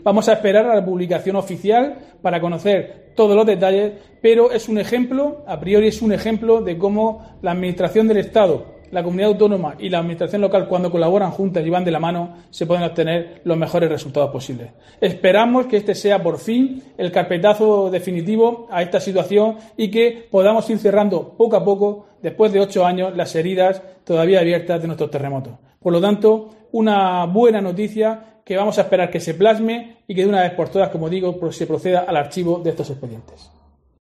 Diego José Mateos alcalde de Lorca sobre archivo expedientes